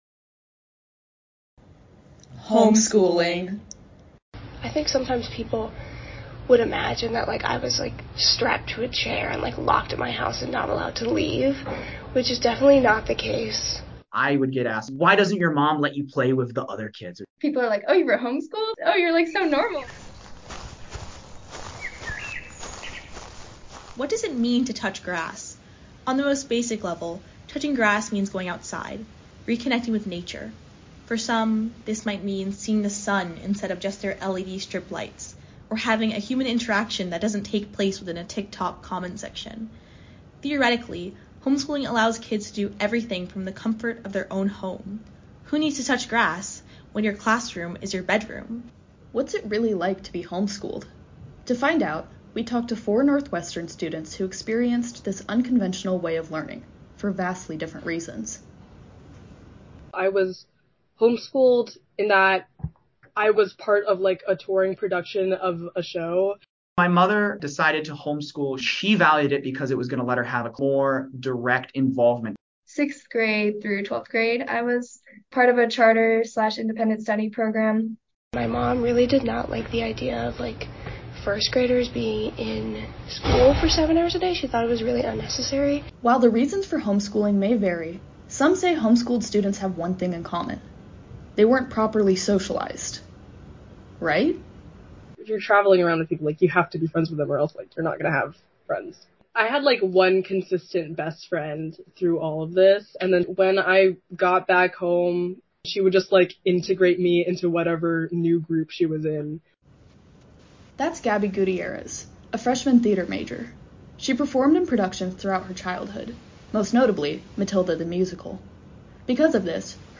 This story originally aired as part of our WNUR News Touches Grass Special Broadcast.